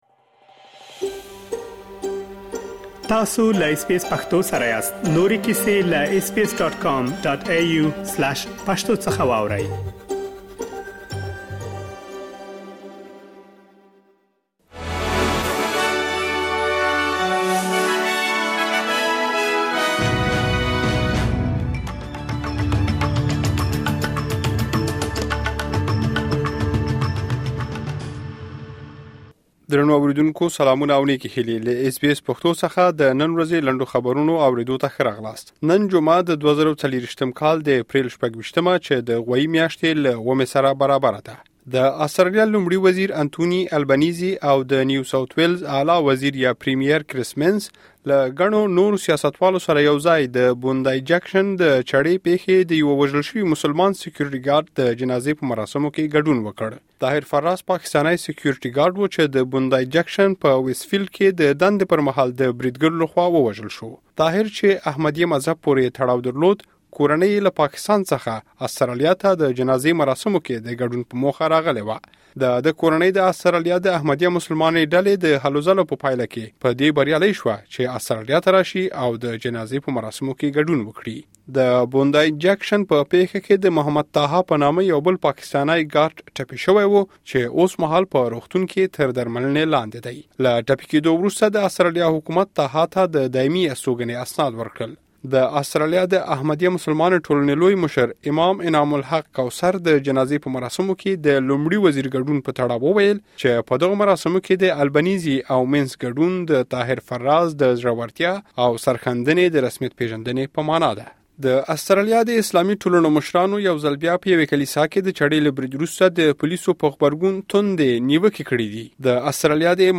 د اس بي اس پښتو راډیو د نن ورځې لنډ خبرونه|۲۶ اپریل ۲۰۲۴